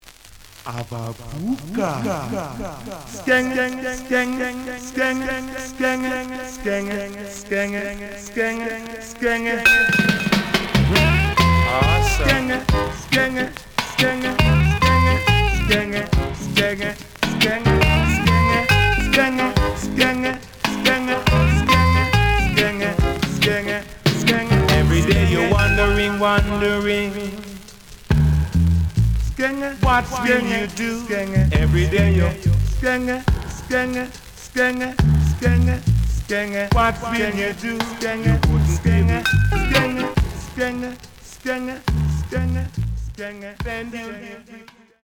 試聴は実際のレコードから録音しています。
●Genre: Reggae
●Record Grading: VG (両面のラベルにダメージ。両面ノイジー。)